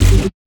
Key-bass_69.4.1.wav